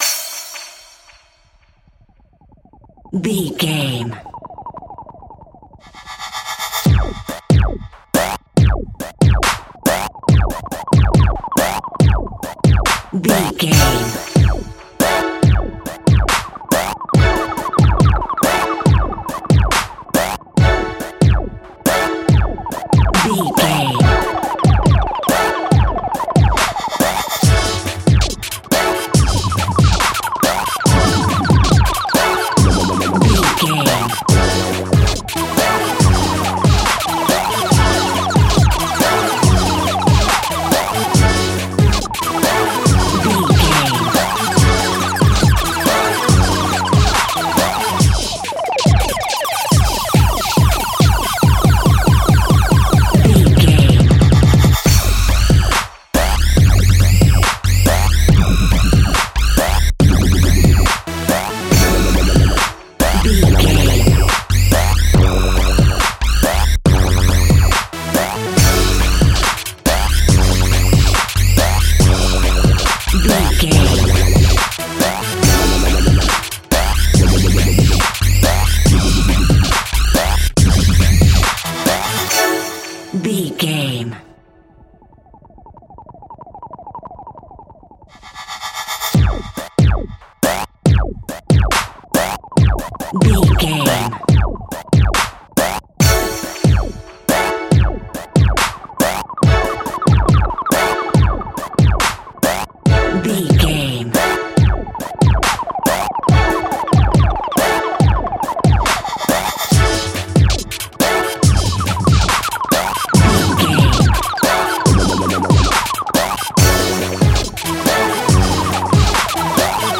Fast paced
Aeolian/Minor
F#
aggressive
dark
funky
groovy
futuristic
energetic
drum machine
synthesiser
breakbeat
synth leads
synth bass